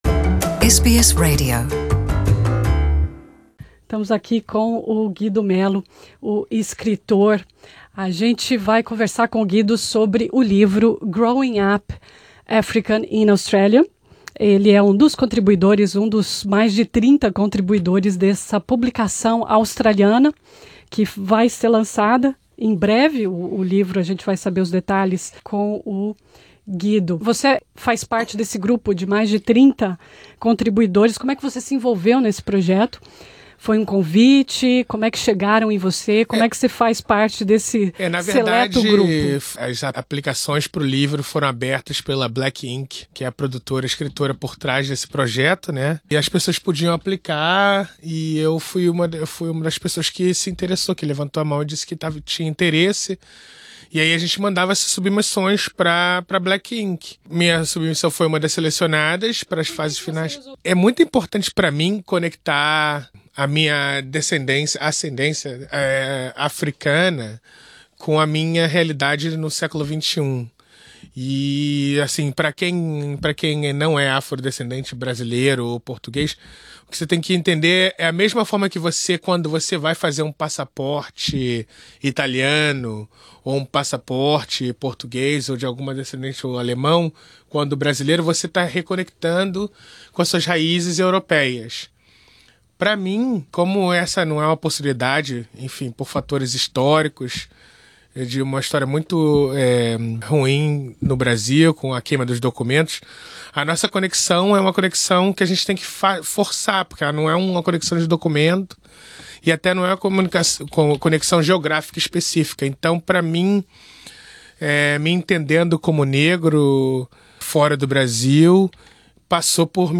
O livro foi publicado pela Black Inc. Nessa entrevista a SBS em Português